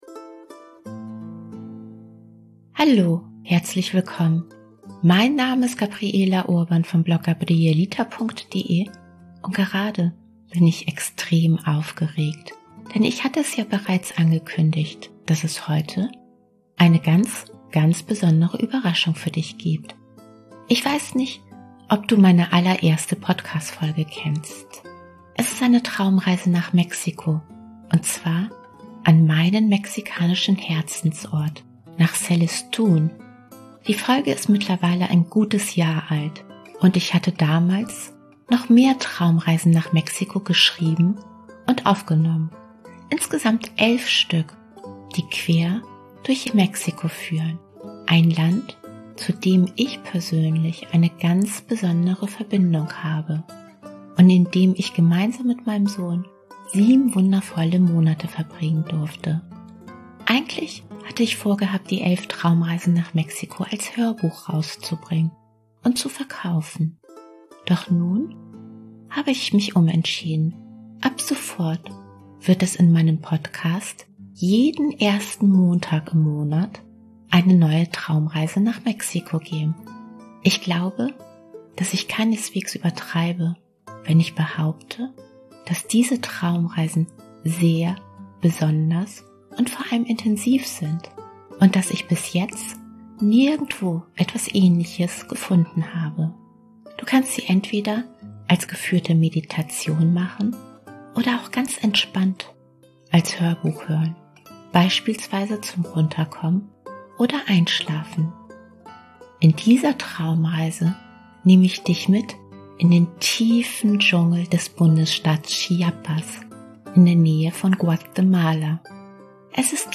Und gemeinsam werden wir die Schönheit ihrer üppigen Dschungelheimat entdecken – und mit allen Sinnen wahrnehmen. Du kannst die Traumreise entweder als geführte Meditation machen oder als Hörbuch zum Entspannen machen, wenn du zum Beispiel von einem stressigen Tag runterkommen möchtest – oder als Begleitung zum Einschlafen.